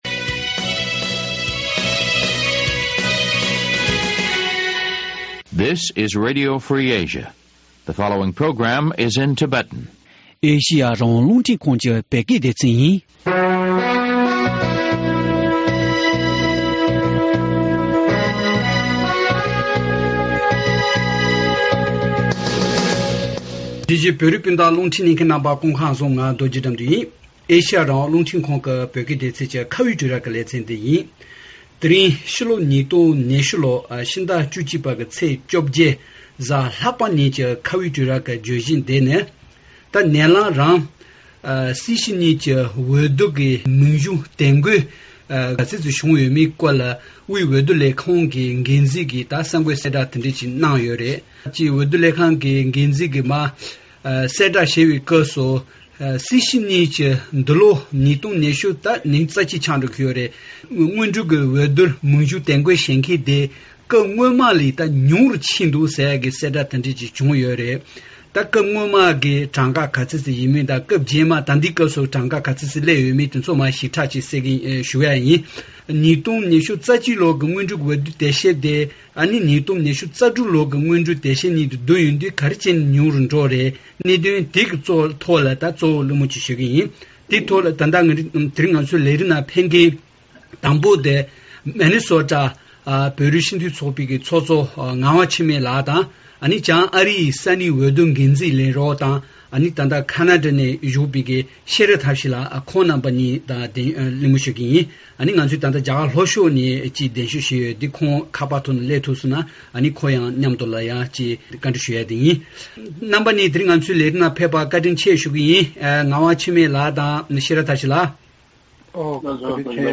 ད་རེས་ཀྱི་གླེང་མོལ་ནང་སྐུ་མགྲོན་གསུམ་ཡོད